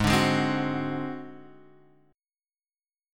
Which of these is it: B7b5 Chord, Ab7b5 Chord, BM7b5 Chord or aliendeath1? Ab7b5 Chord